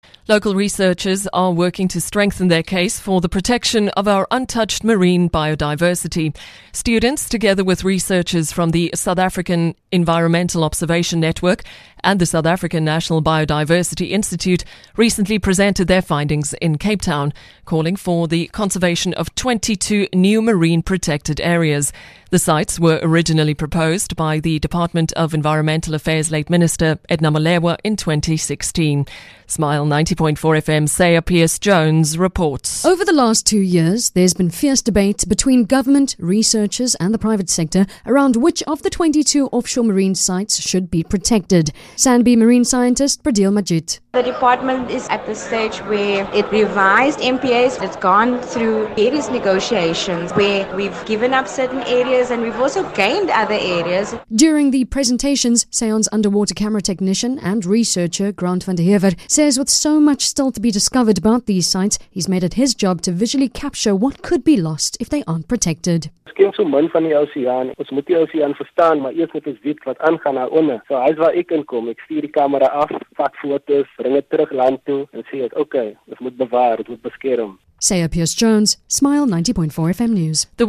Students, alongside researchers from SAEON and the South African National Biodiversity Institute (SANBI), recently presented their findings in Cape Town, calling for the conservation of 22 new marine protected areas. SmileFM broadcast a news item on the event on September 28, featuring a